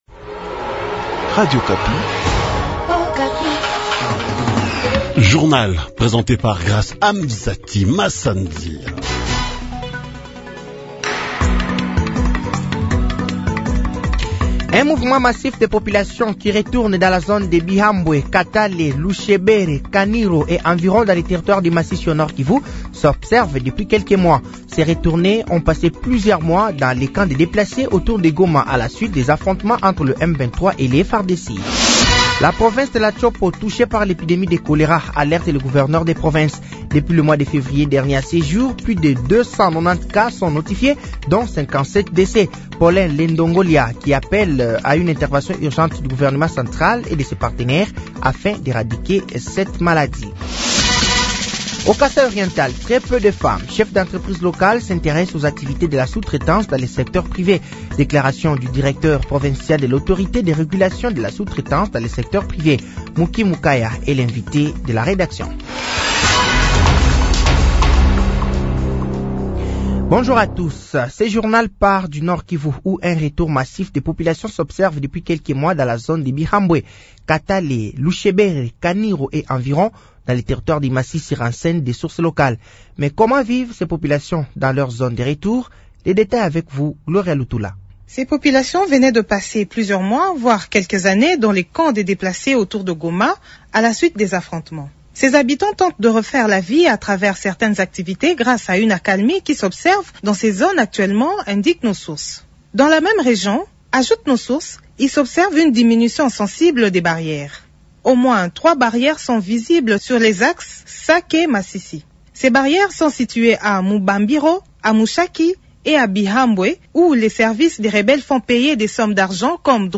Journal français de 08 de ce mercredi 09 avril 2025